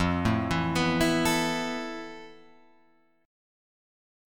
FM#11 chord